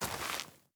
added stepping sounds
Ice_Mono_03.wav